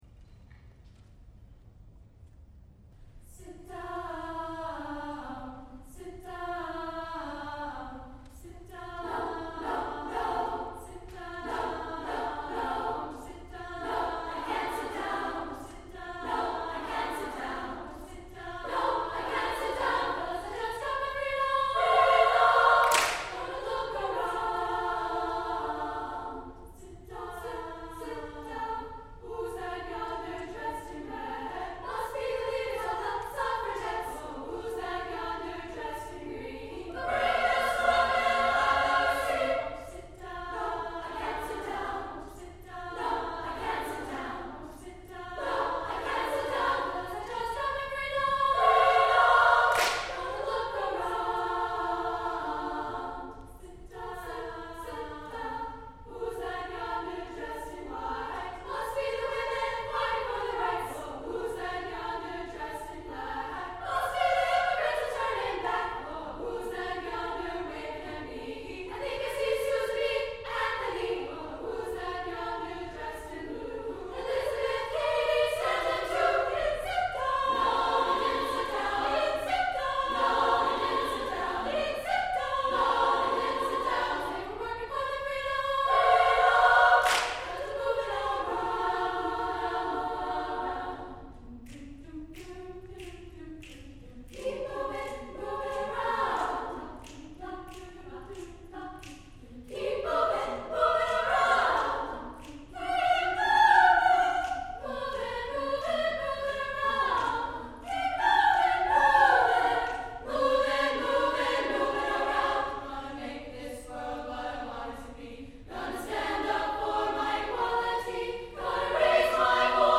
for SSAA Chorus (2000-02)
The Spirit of Women is a set of three songs for Women's Chorus, a cappella.
The musical alterations to the original song include "blues" harmonies and hand-claps (for joy!).